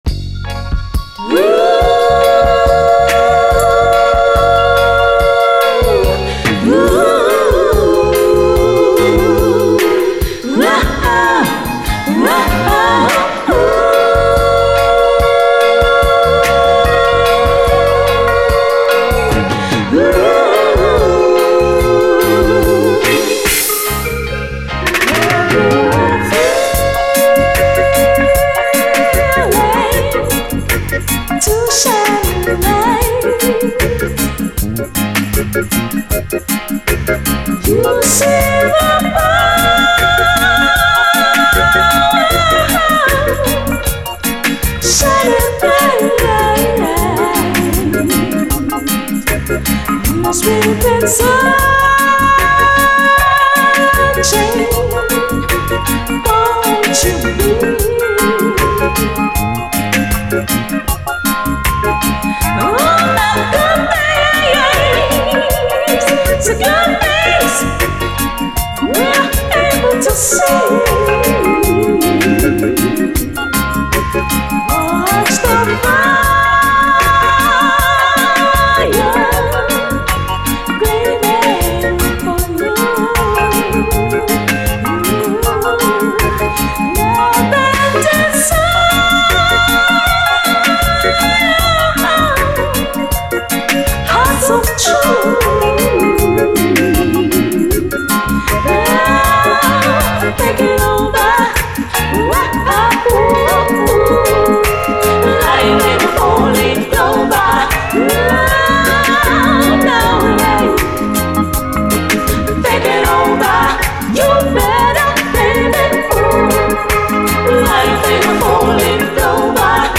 REGGAE
超絶ドリーミーなキラー・レアUKラヴァーズ！
「DUB」